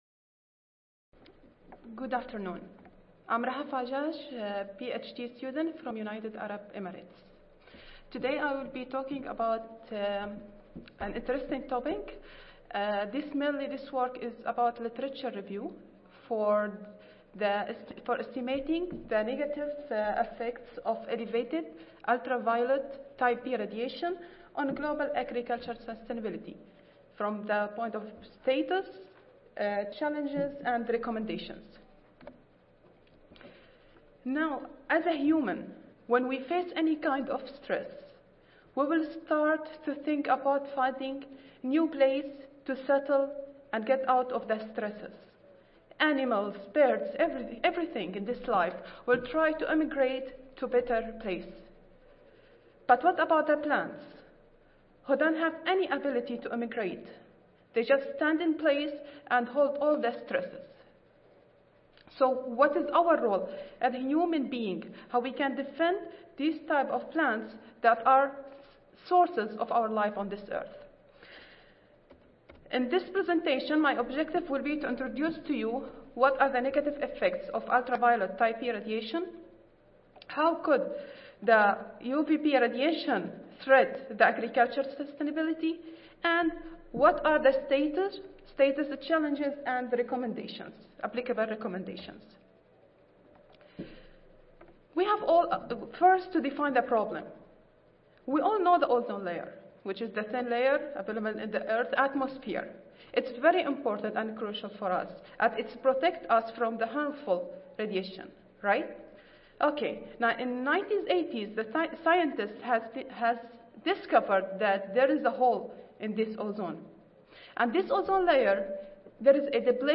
United Arab Emirates University Audio File Recorded Presentation